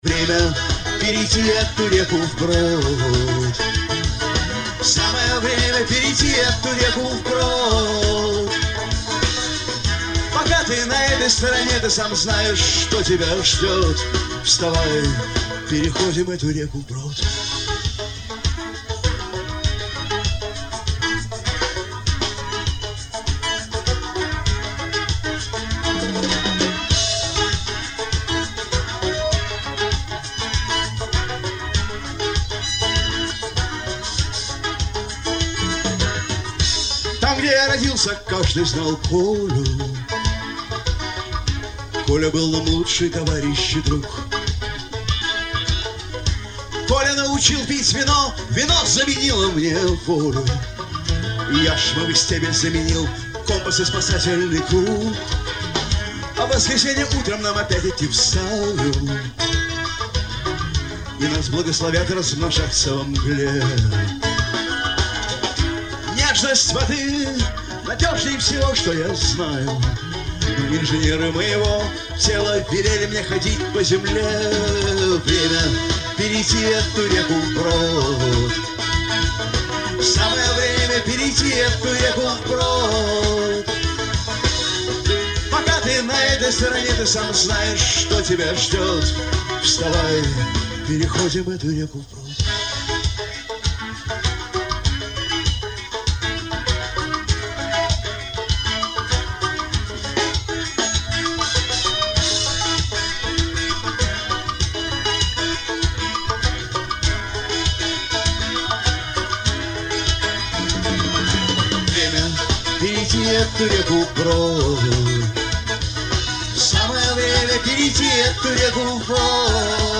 концерт в Самаре